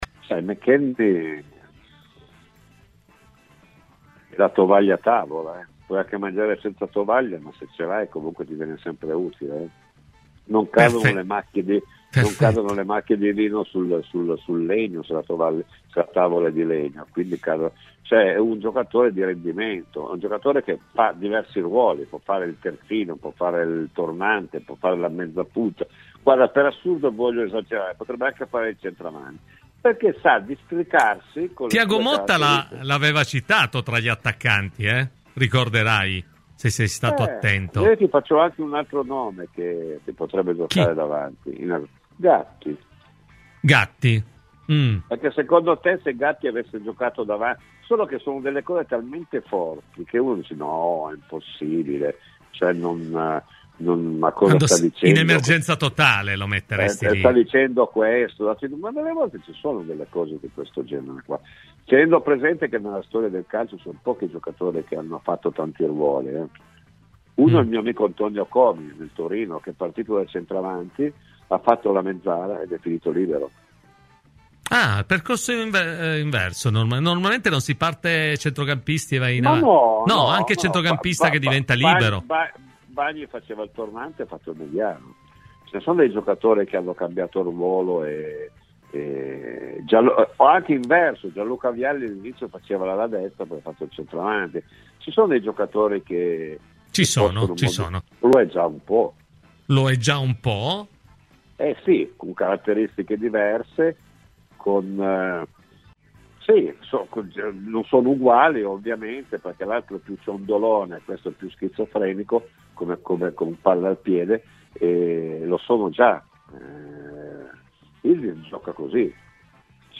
Domenico Marocchino ha parlato ai microfoni di Radio Bianconera dei problemi in attacco della Juventus, che in questo mercato sembra ormai orientata verso l'acquisto di En-Nesyri, centravanti marocchino del Fenerbahce: “L'ideale per i bianconeri sarebbe avere la capacità di trovare un ragazzo giovane che gli possa garantire anche un futuro per il reparto – le sue parole nel corso di “Cose di Calcio” – questa sarebbe la soluzione migliore in assoluto”.